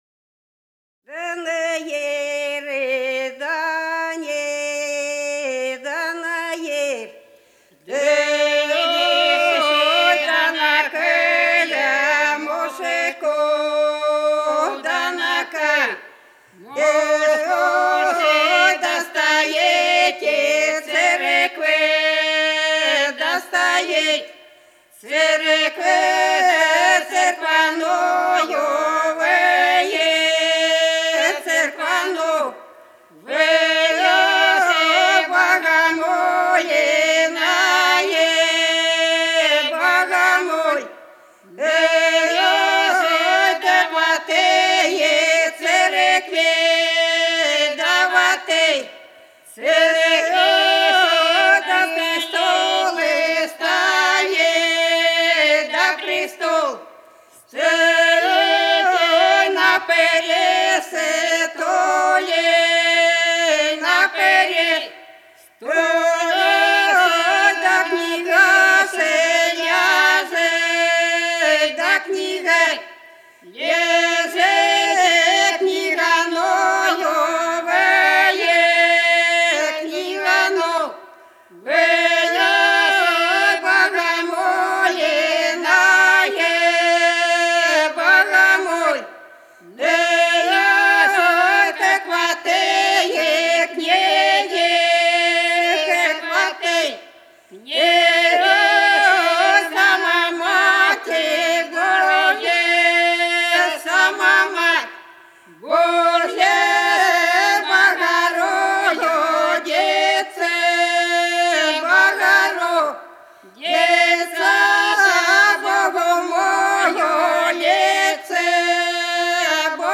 Голоса уходящего века (село Фощеватово) Да на Ердане (трио)
7._Да_на_Ердане,_дуэт.mp3